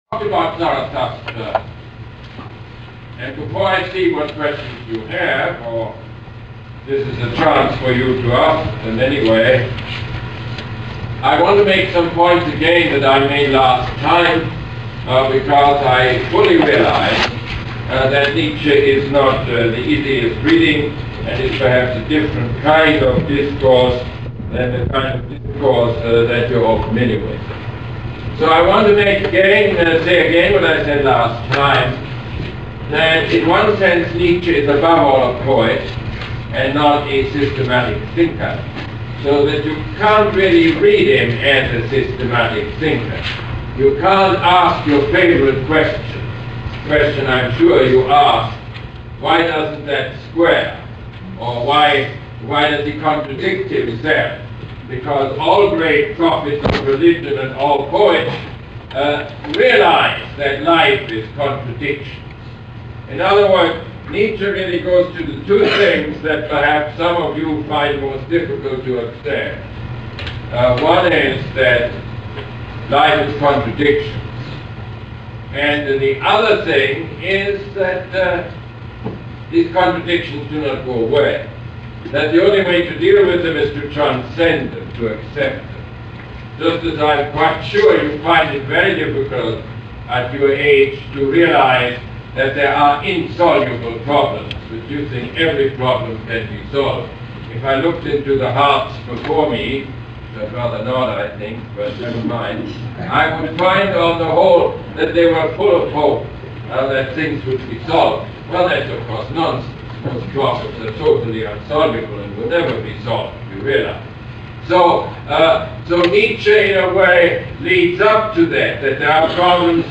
Lecture #8 - October 8, 1979